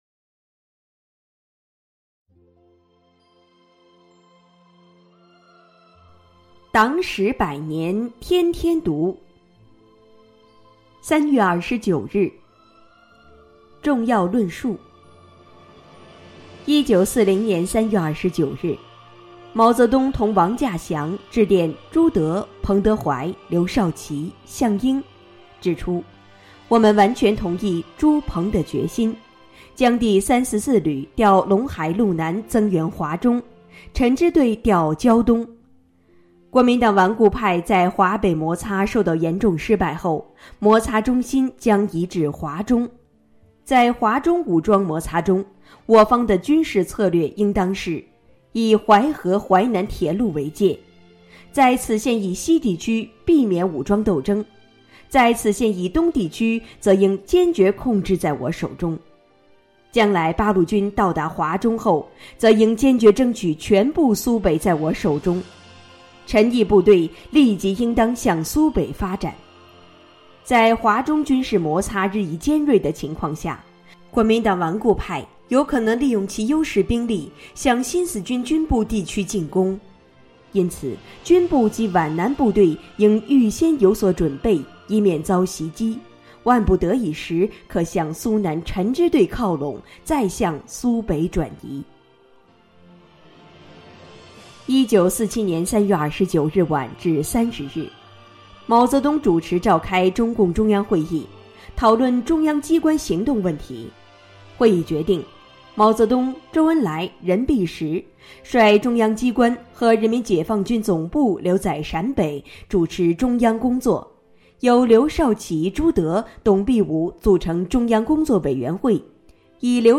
朗读